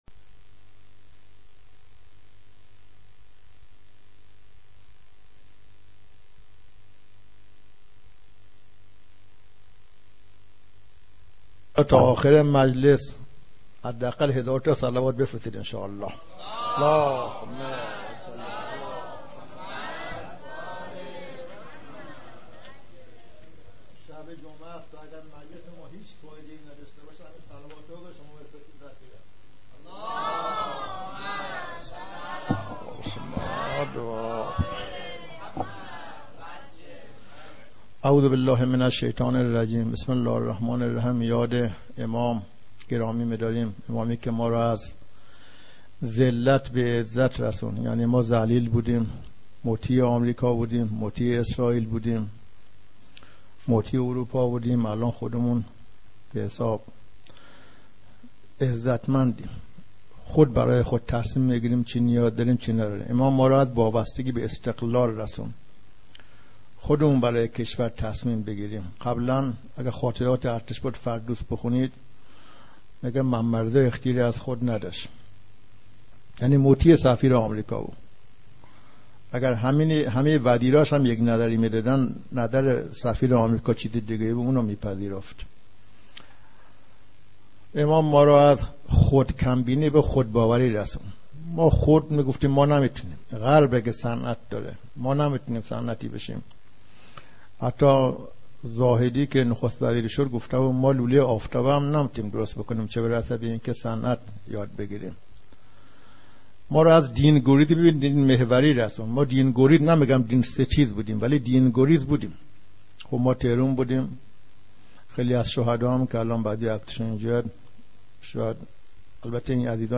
هیئت مهدیه احمد آباد - سخنرانی دفاع مقدس